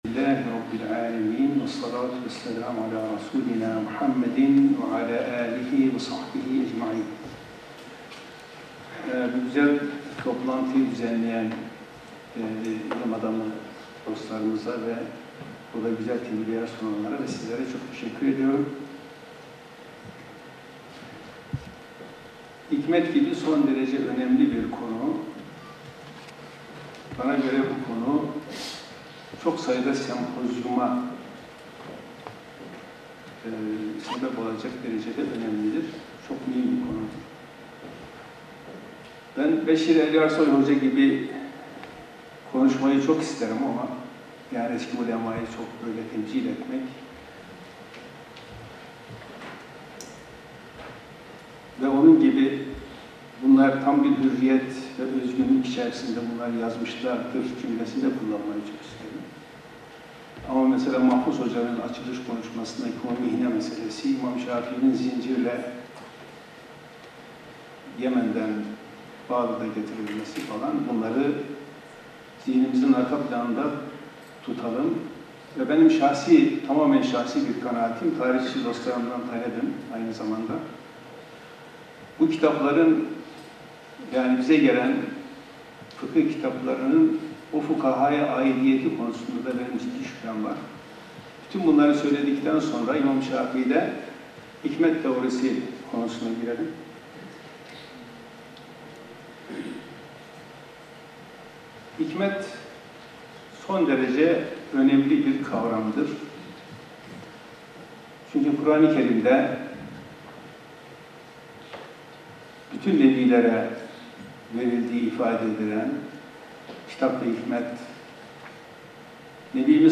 Konferanslar